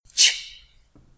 ch